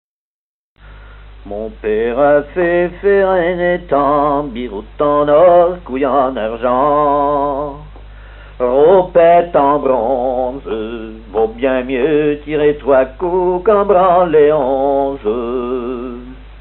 gestuel : à marcher
Genre laisse
Pièce musicale inédite